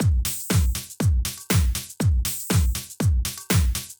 Drumloop 120bpm 09-A.wav